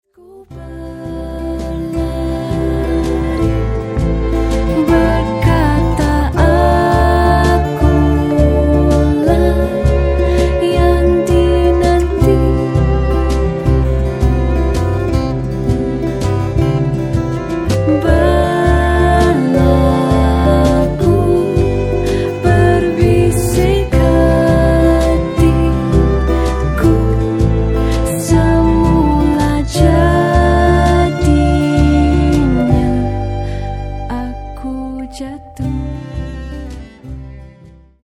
インディポップ/ワールド